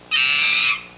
Gibbon (short) Sound Effect
Download a high-quality gibbon (short) sound effect.
gibbon-short.mp3